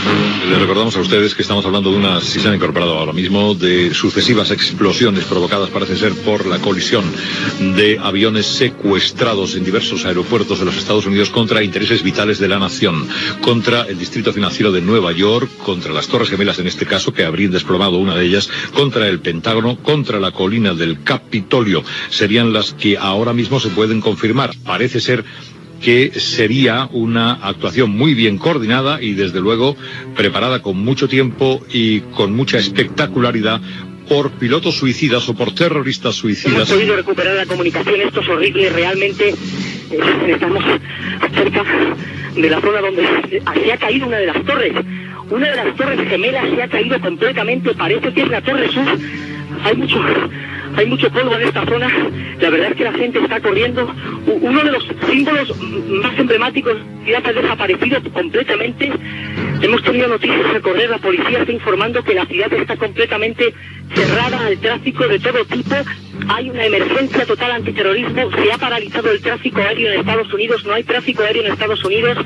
Informatiu
Fragment extret del programa "90 años de Radio Barcelona" emès per Ràdio Barcelona el 14 de novembre de 2014.